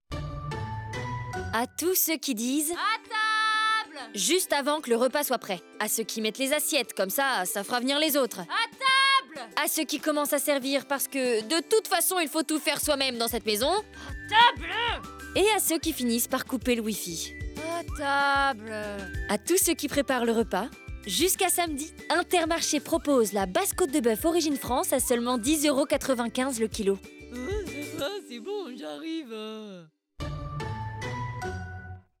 Maquette Radio - INTERMARCHE
Voix off
10 - 30 ans - Mezzo-soprano Soprano